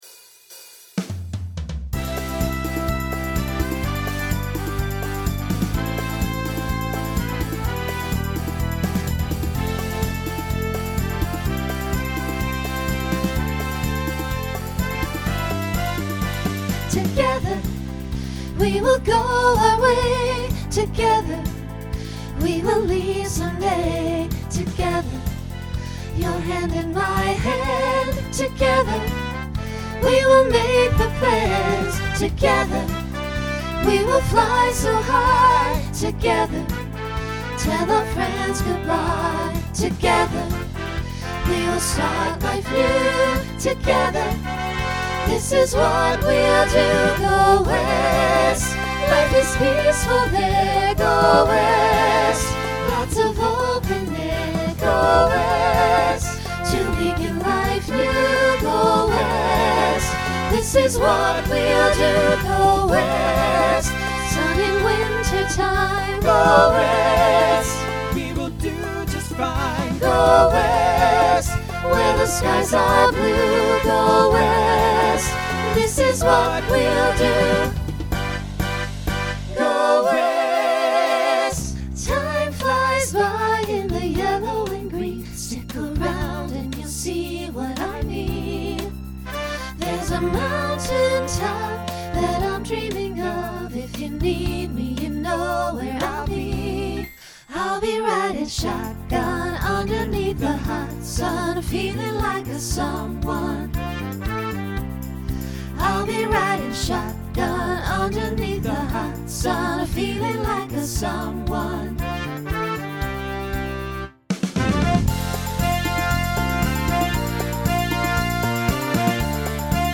Genre Country , Disco , Rock
Voicing SATB